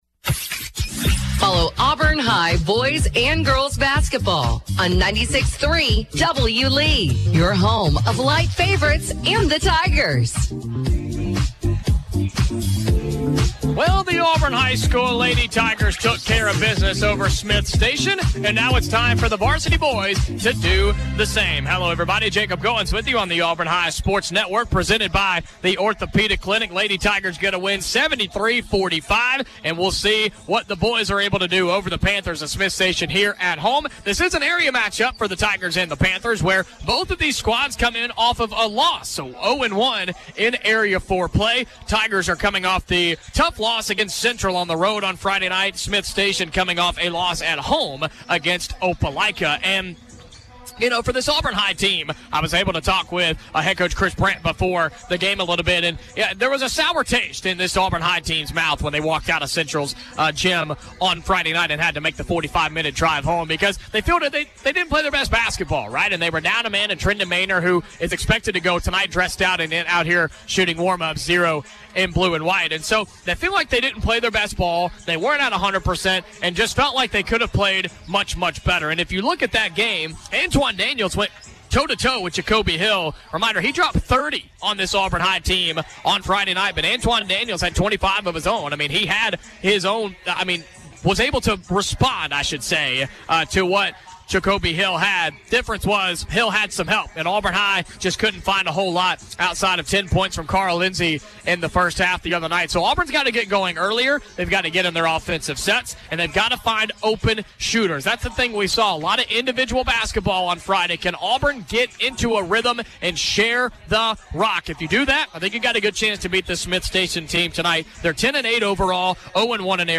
Jan. 7, 2025 - Listen as Auburn High hosts Smiths Station. The Tigers won 64-46.